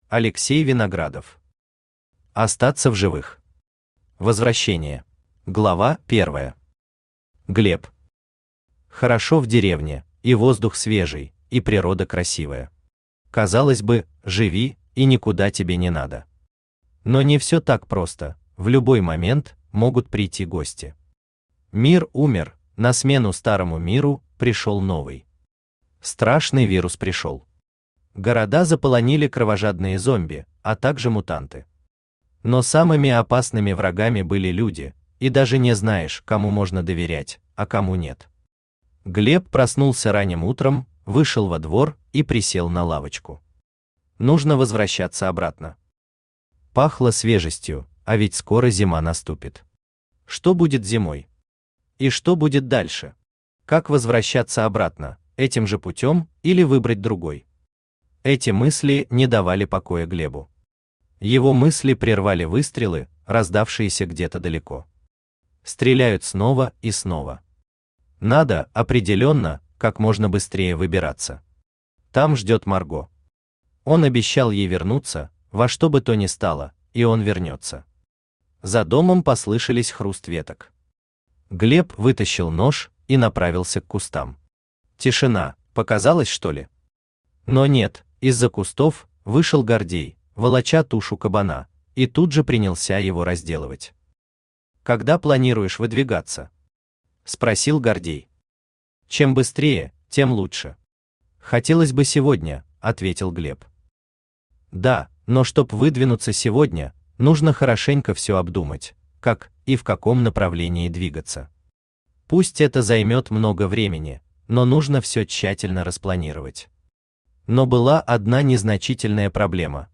Аудиокнига Остаться в живых. Возвращение | Библиотека аудиокниг
Возвращение Автор Алексей Вениаминович Виноградов Читает аудиокнигу Авточтец ЛитРес.